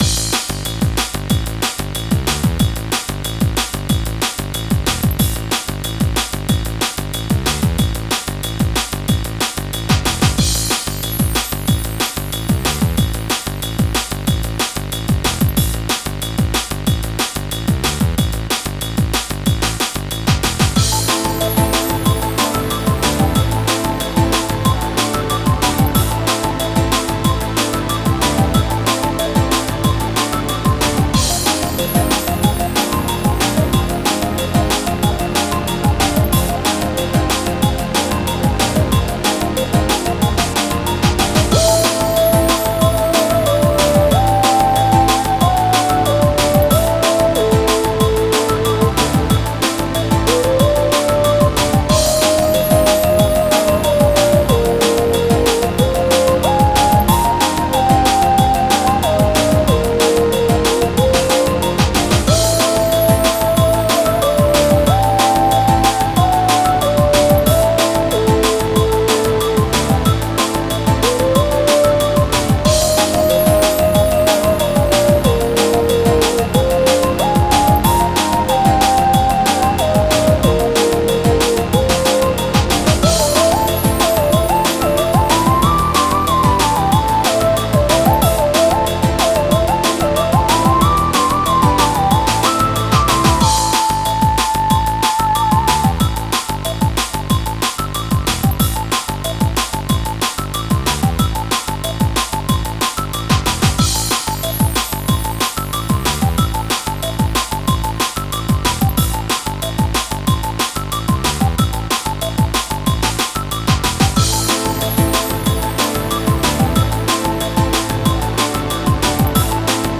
侵食される、悪化するイメージの緊迫感があるクールなエレクトロニカ。...